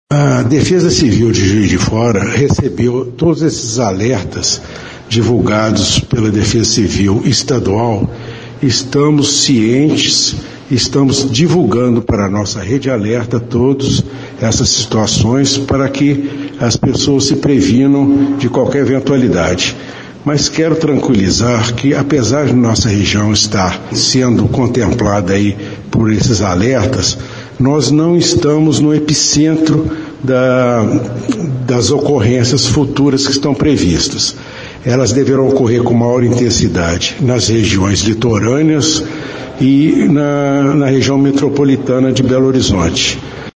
O subsecretário da Defesa Civil de Juiz de Fora (MG), Jefferson Rodrigues, explicou que a cidade está atenta à situação.